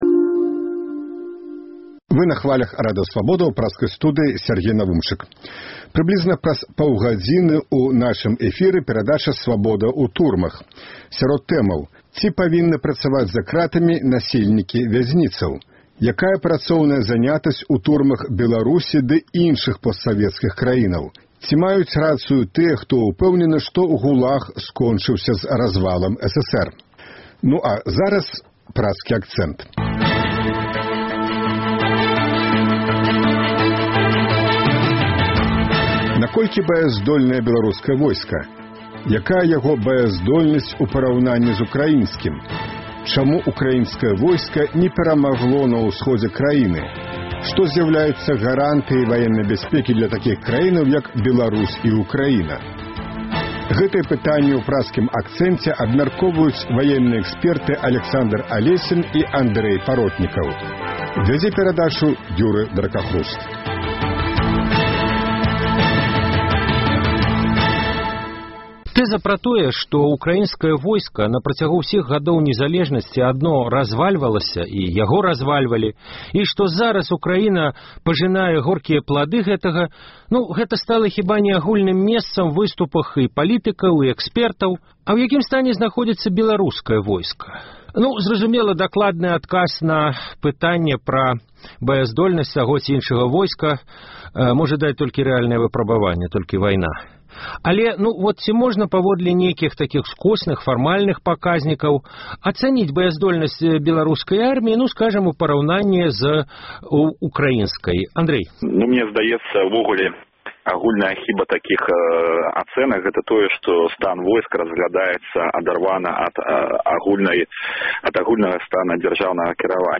абмяркоўваюць ваенныя экспэрты